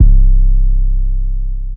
Metro Southside 808.wav